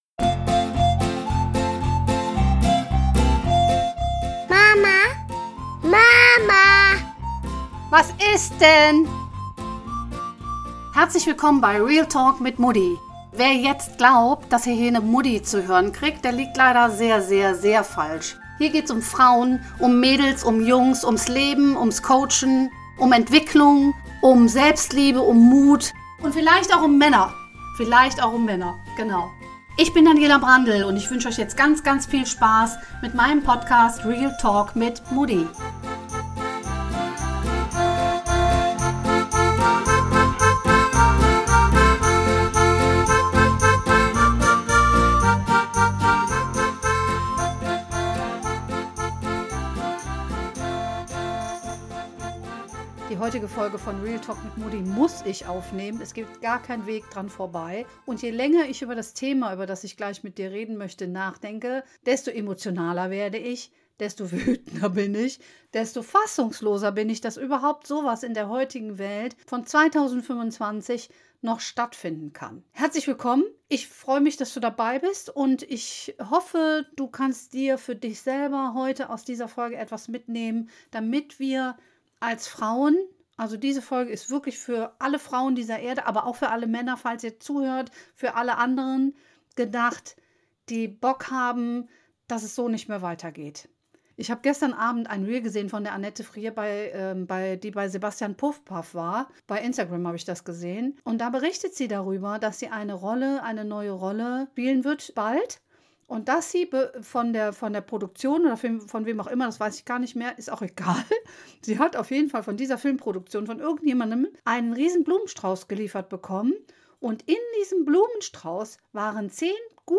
Die verwendete Musik wird zur Verfügung gestellt